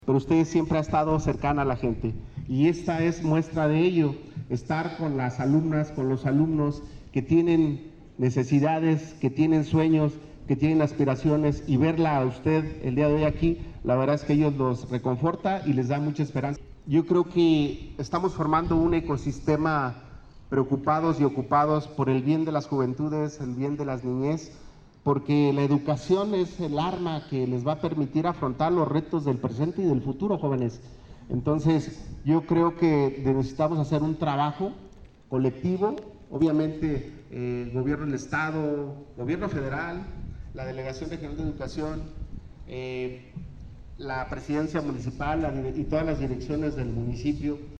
Irapuato, Gto. 21 de octubre del 2024 .- El programa anual de actos cívicos inició en el Conalep II, con la conmemoración del mes de prevención del cáncer de mama y del primer voto de las mujeres en 1953.
Nicolás Gutiérrez Ortega, director general del Conalep Guanajuato